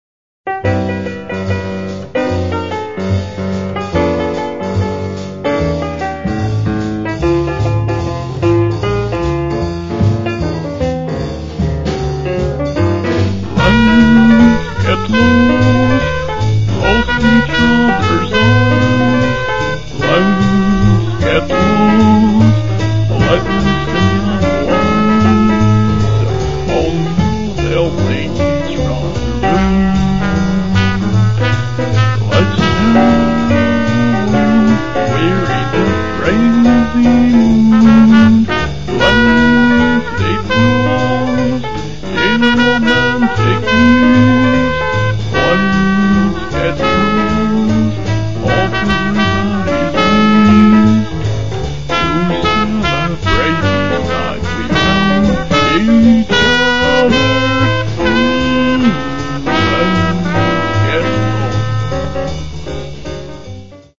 I love singing even though I stink at it.